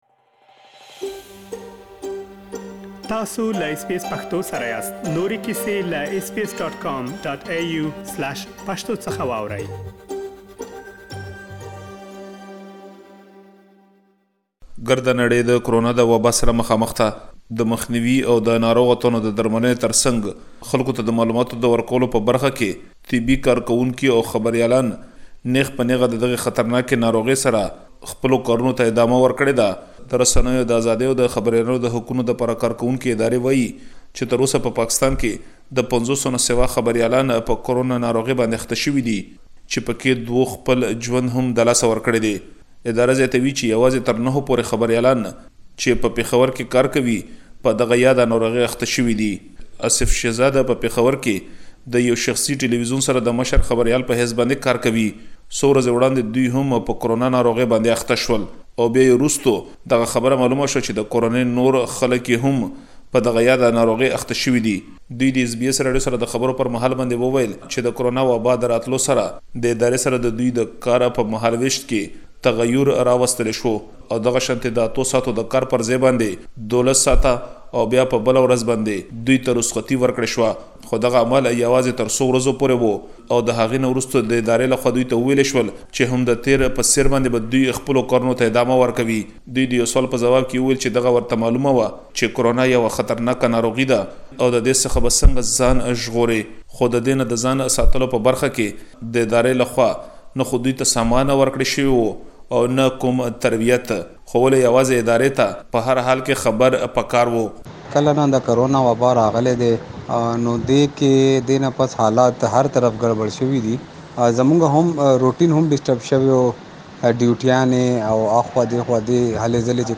له ځينو خبريالانو سره خبرې کړې چې تاسې د هغوی بشپړې خبرې دلته اوريدلی شئ.